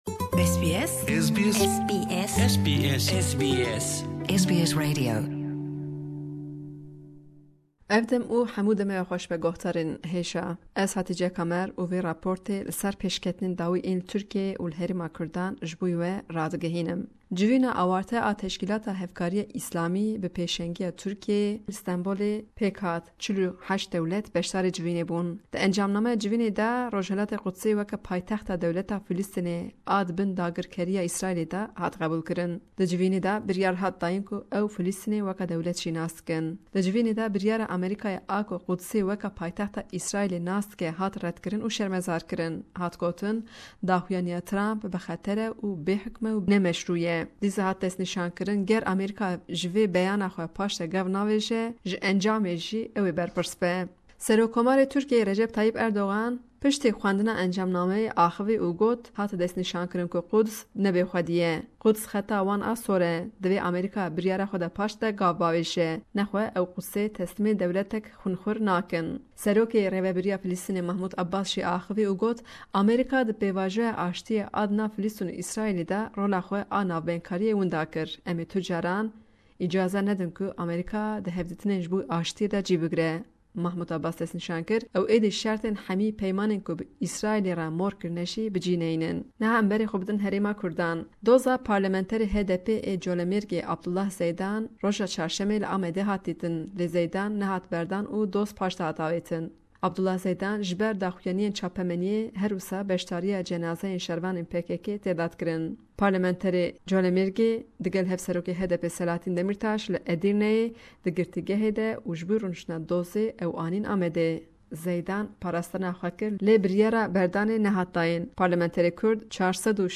Ji raporta peyamnera me ji Amedê: mijara pêşî civîna awarte ya Teşkilata Hevkariya Îslamê bi pêşengiya Tirkiyê li Stenbolê pêk hat.